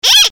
clock10.mp3